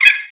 added another tire squeal
squeal2.wav